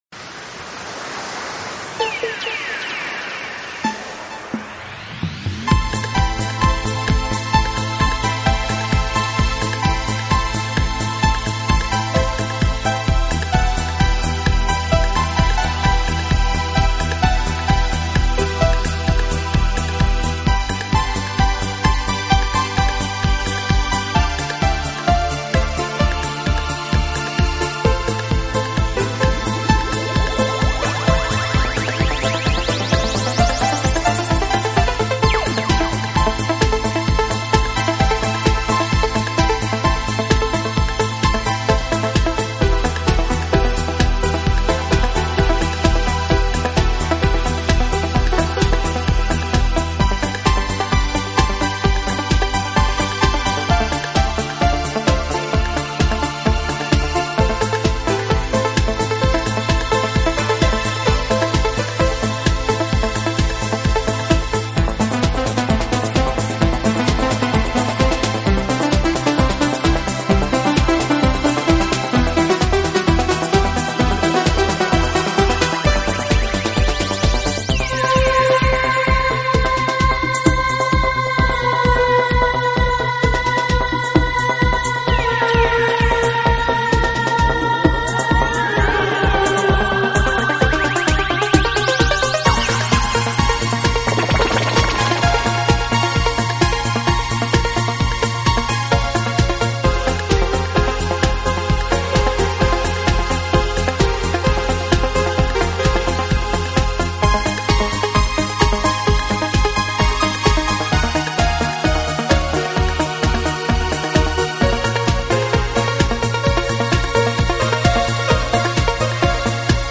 Chill Out和Easy Listening之间的东西”，这与大多数 传统的德国前卫电子音乐
和整体相对"happy"的曲调。